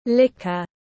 Rượu mạnh tiếng anh gọi là liquor, phiên âm tiếng anh đọc là /ˈlɪk.ər/